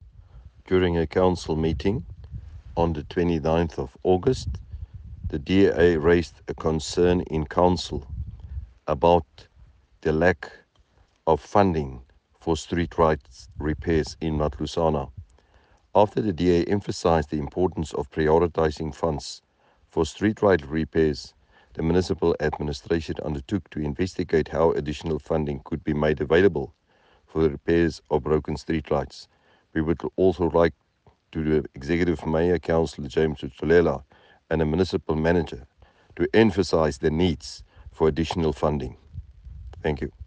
Note to Broadcasters: Please find linked soundbites in
Cllr-Johannes-le-Grange-Streetlights-Eng.mp3